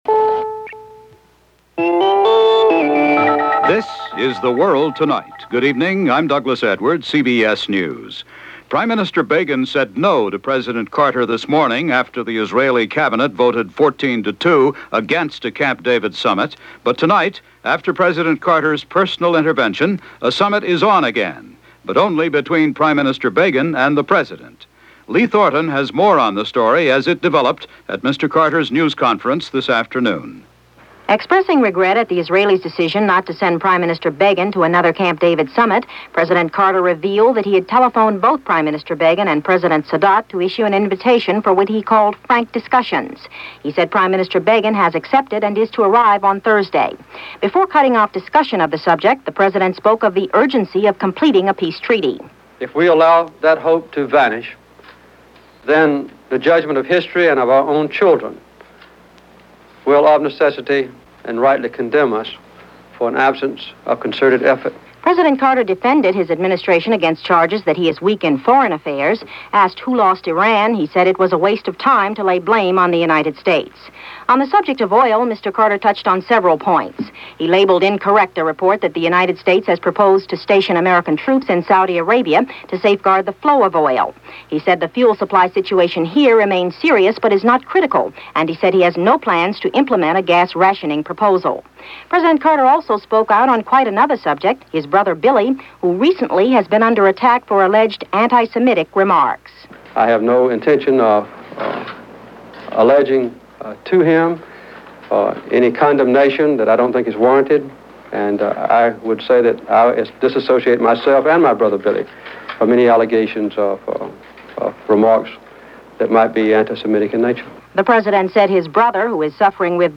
CBS The World Tonight + Walter Cronkite Comments